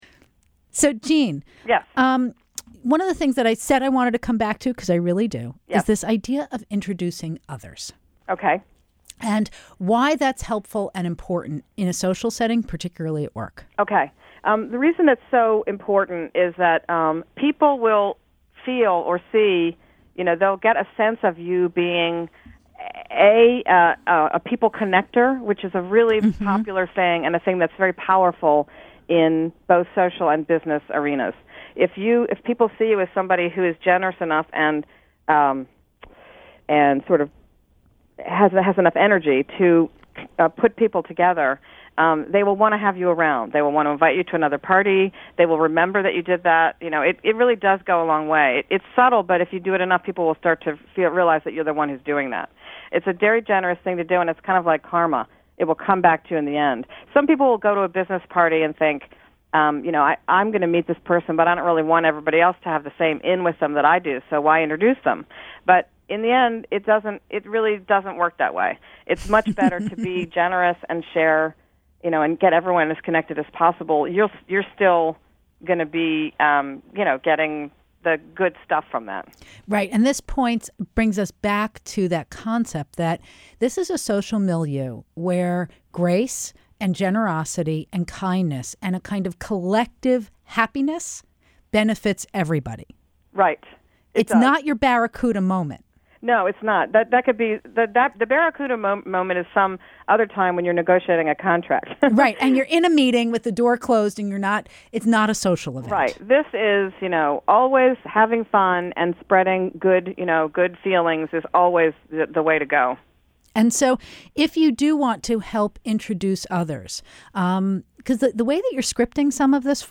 Selected Media Appearances and Interviews